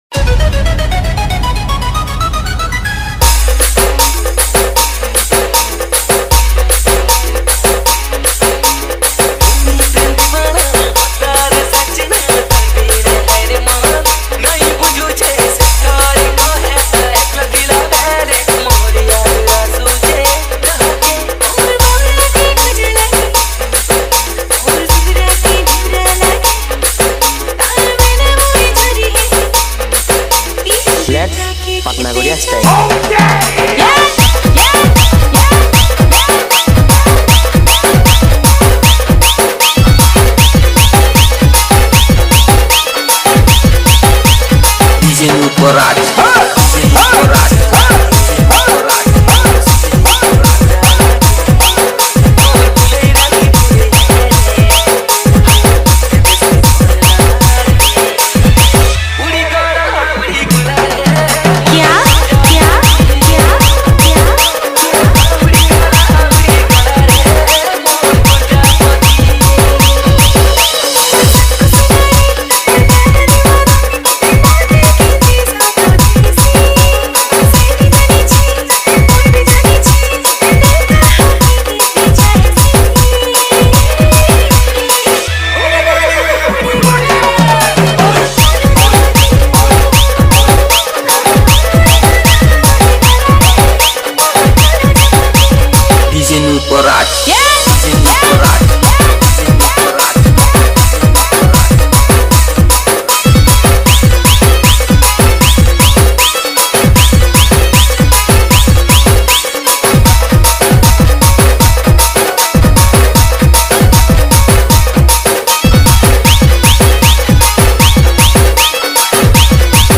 Sambalpuri Dj Song 2024
Category:  New Sambalpuri Dj Song 2023